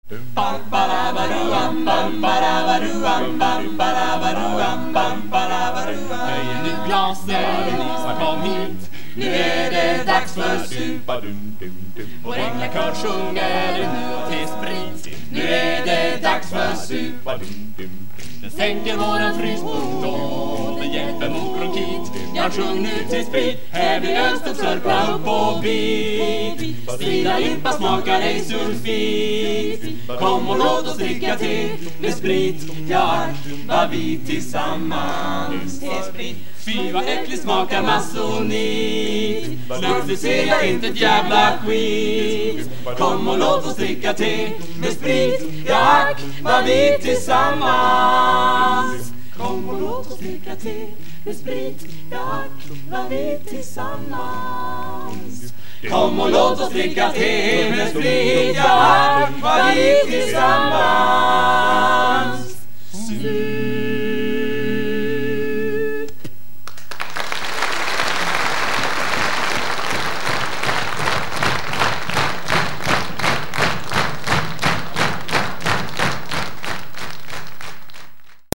Låtar som av någon anledning valts ut från spex-93.
Omstart till tespritsvisan. wav